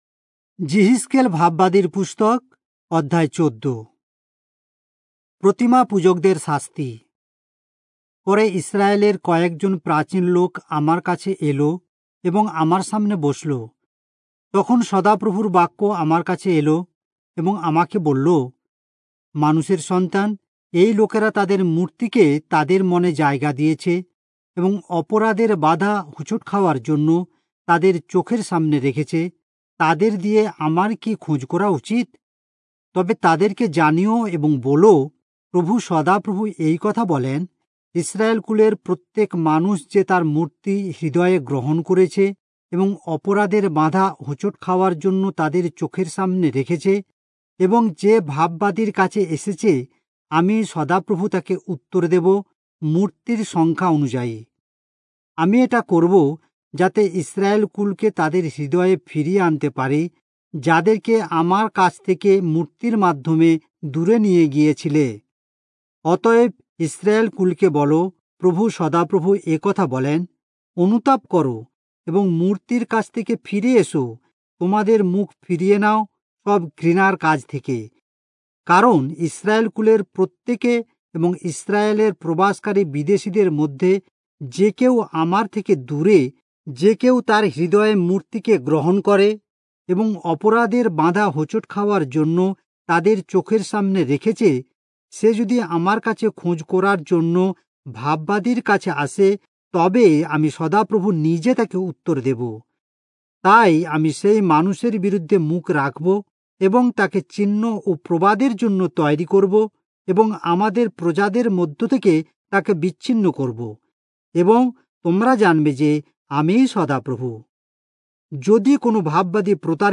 Bengali Audio Bible - Ezekiel 34 in Irvbn bible version